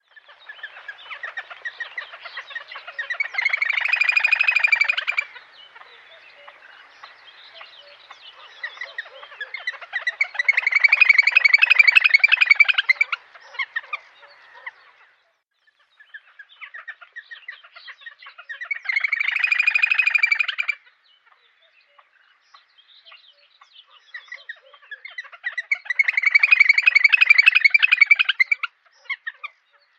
Illustration grèbe castagneux
grebe-castagneux.mp3